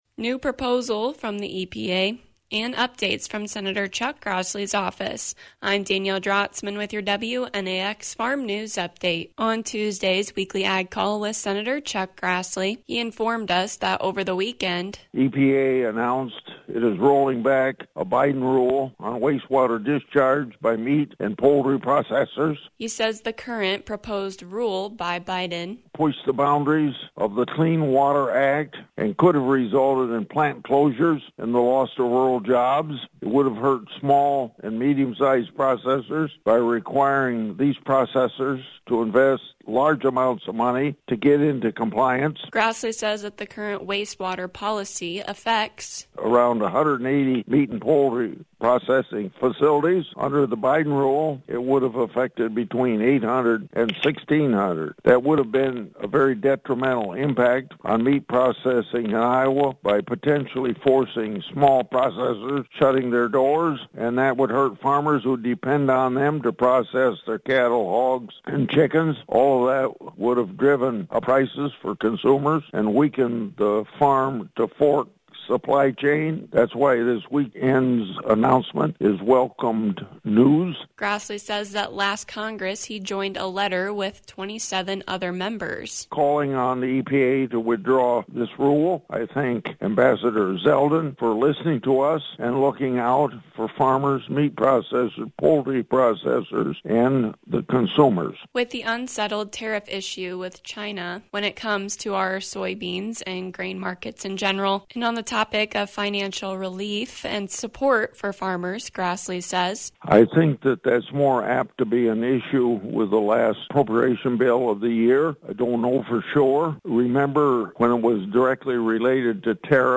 Hear from Senator Chuck Grassley as he discusses recent happenings with the EPA and how renewable fuels is another important revenue stream for farmers.